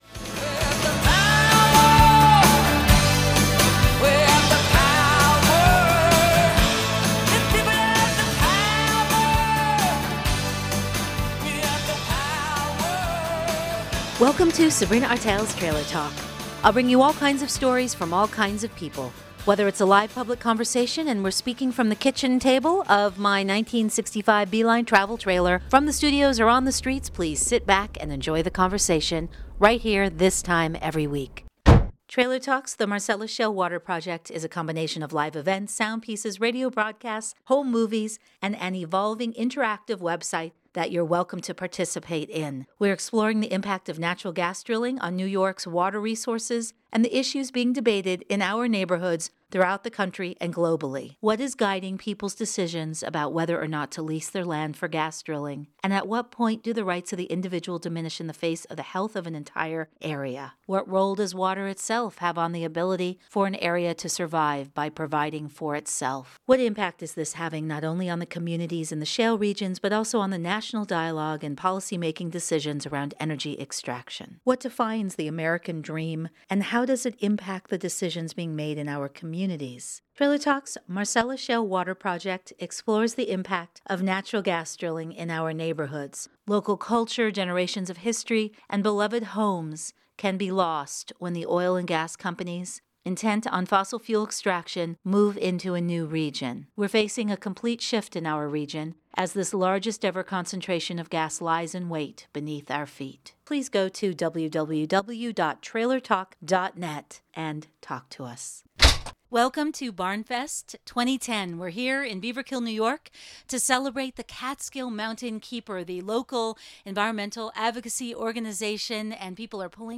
Bill McKibben , environmentalist and 350.org founder joins Trailer Talk to speak about his book, EAARTH at the Mountainkeeper Barnfest in Beaverkill, N.Y., written to scare the pants off of us (inform) and remind us that building a local economy is essential while building a global movement to get off of fossil fuels and stop the powerful energy corporations from destroying our home (the planet and our neighborhoods).